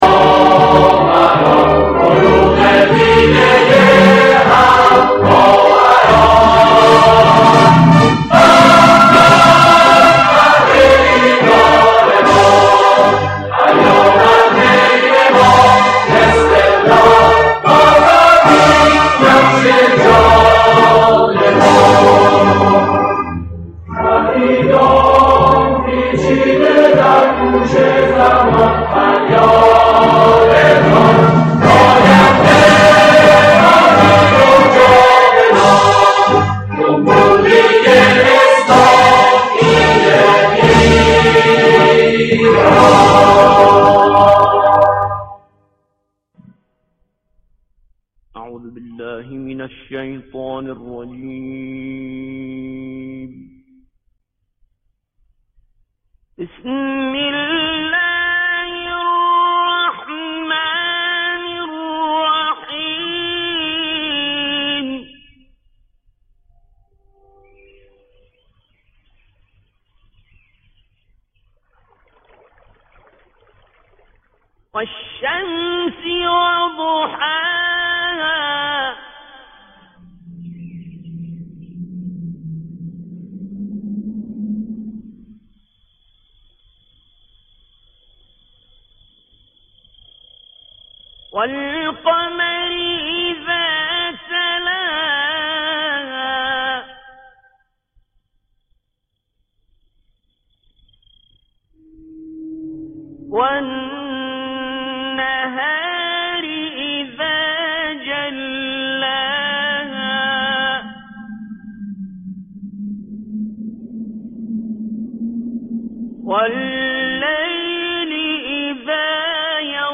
مجمع عمومی فوق العاده شرکت صنعتی آما - نماد: فاما